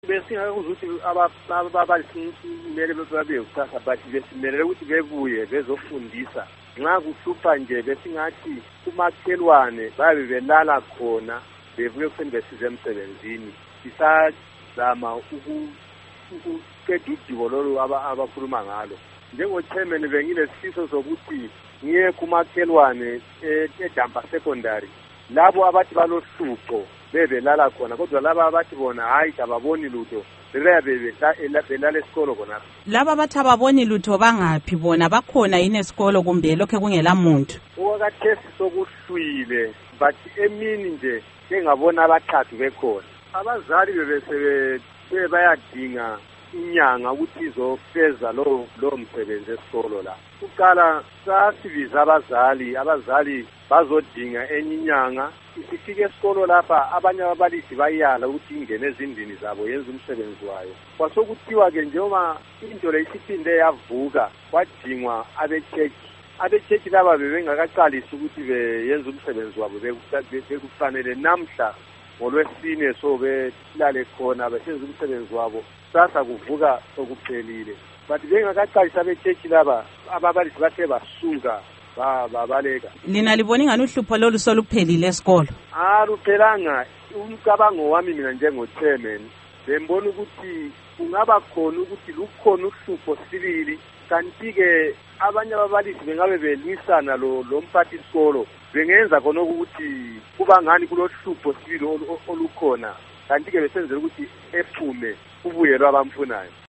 Embed share Ingxoxo LoMnu.